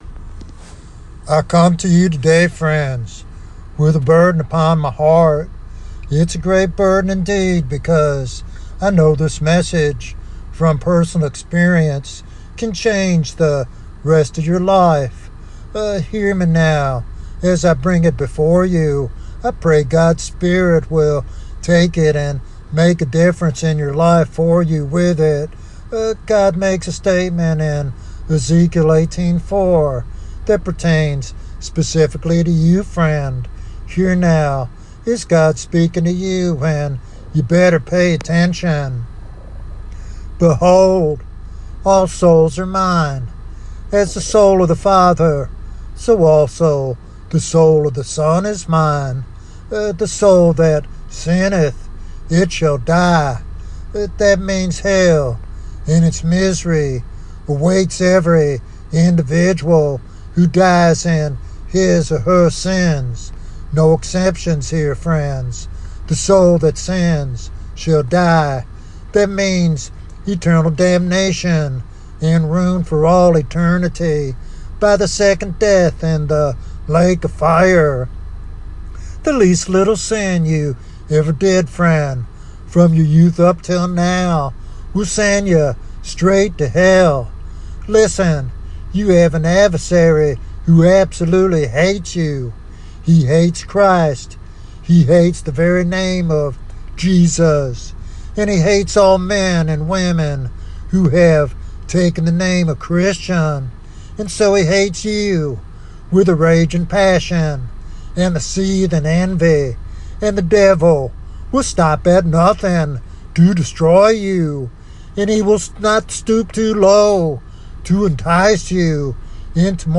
This sermon challenges Christians to examine their hearts, reject sin fully, and stand firm in their commitment to Jesus.